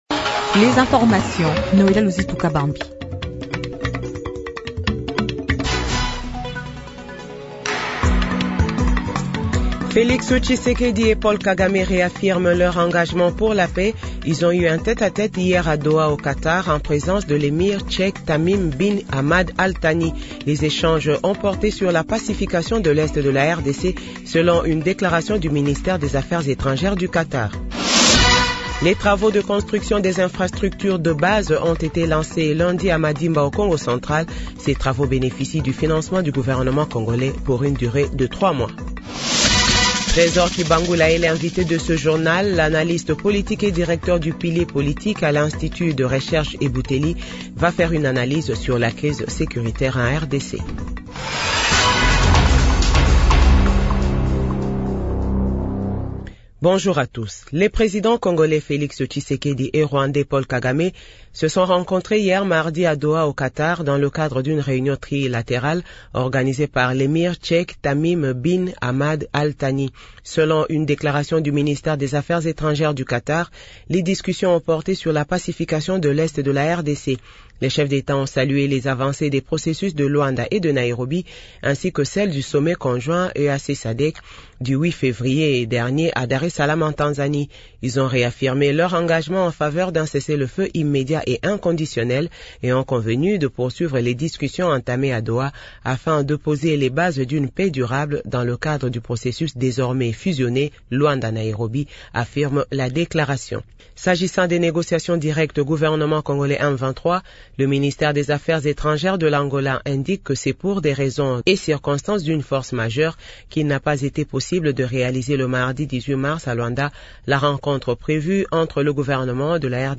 Journal 8h